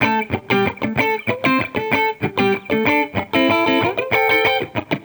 Index of /musicradar/sampled-funk-soul-samples/95bpm/Guitar
SSF_TeleGuitarProc1_95A.wav